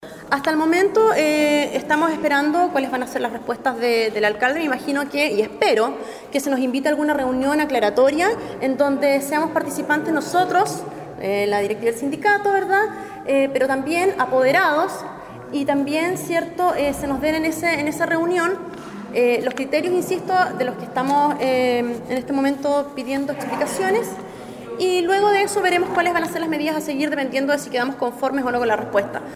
Durante la exposición ante el Concejo instó a las autoridades a generar en el menor tiempo posible las reuniones necesarias para poder aclarar los diferentes despidos que han acontecido en la educación municipal de la comuna.